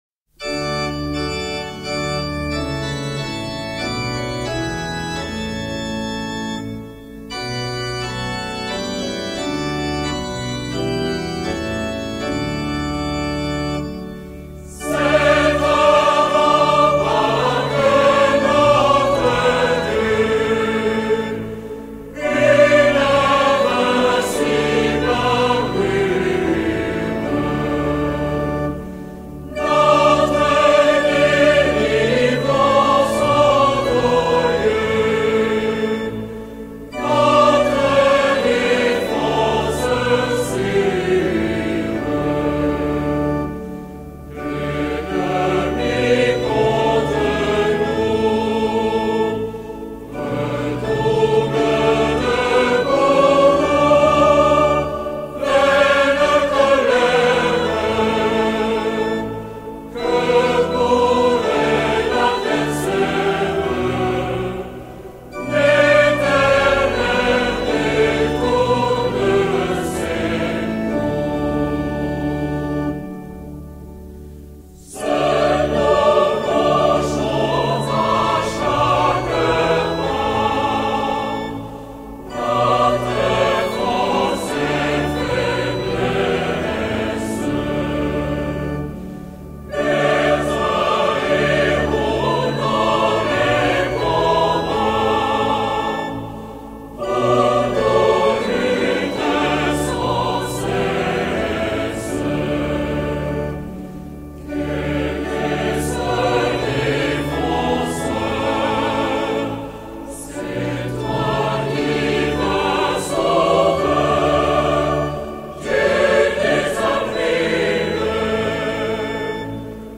Cantique « C’est un rempart que notre Dieu » : Alléluia 37/01